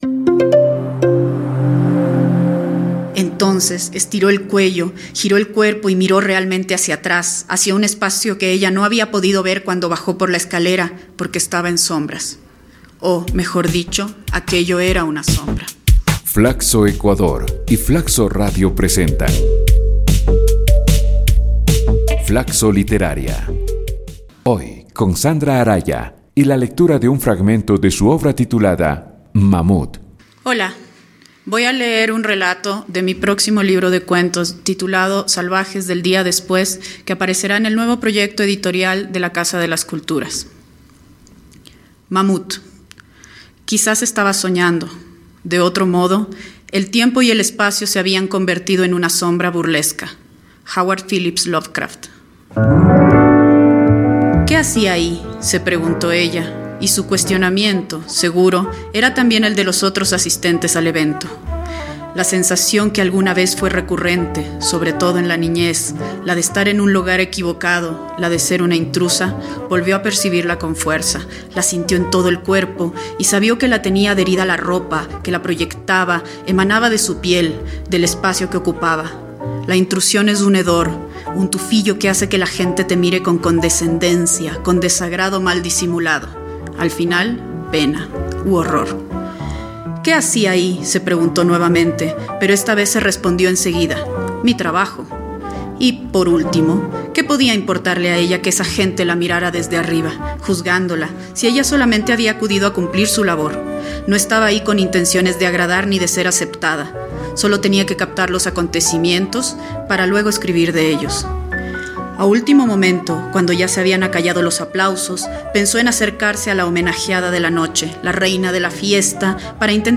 FLACSO Literaria es una iniciativa de lectura de cuentos y fragmentos de novelas que busca promover la literatura ecuatoriana, que reúne a 21 destacados escritores y escritoras quienes leerán sus obras.